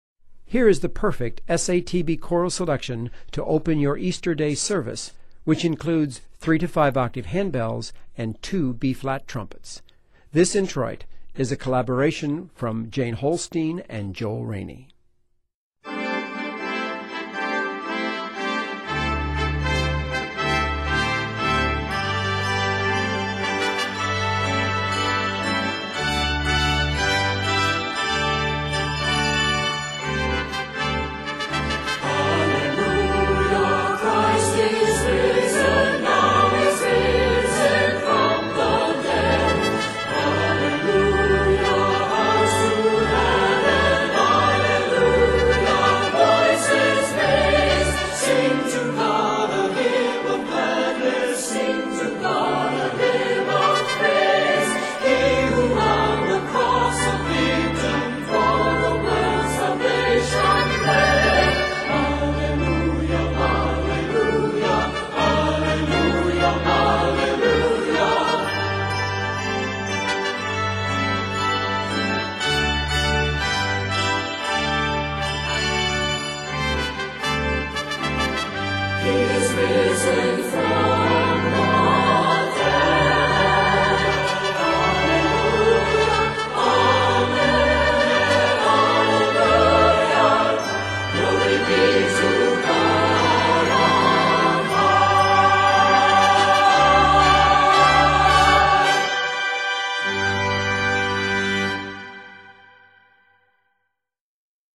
short introit for choir and keyboard
3-5 octaves of handbells and two trumpets
set in G Major and is 55 measures
Varies by Piece Season: Easter